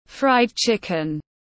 Gà rán tiếng anh gọi là fried chicken, phiên âm tiếng anh đọc là /fraɪd ˈtʃɪk.ɪn/
Fried chicken /fraɪd ˈtʃɪk.ɪn/